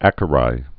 (ăkə-rī)